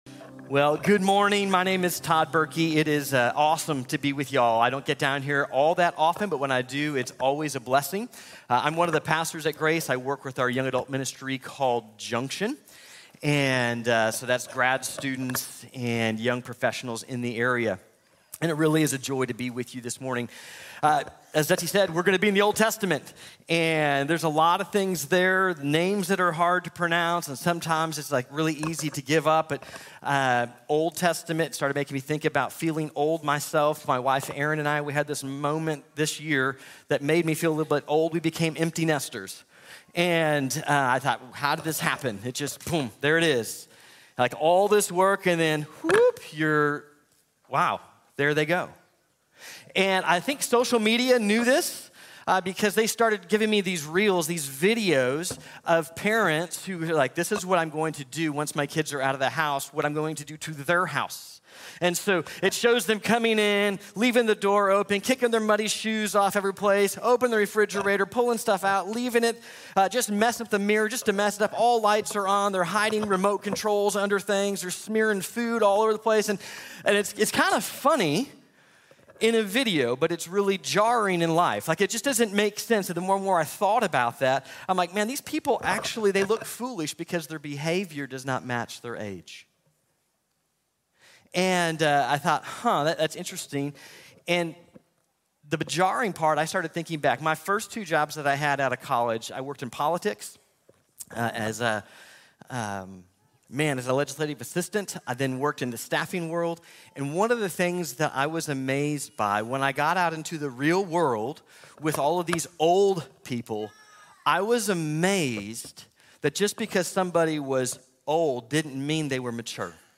Not Everyone Grows | Sermon | Grace Bible Church